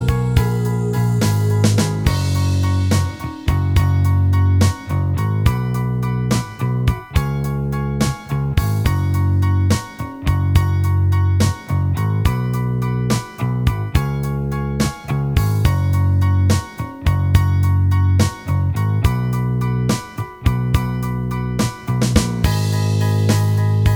Minus Guitars Rock 3:36 Buy £1.50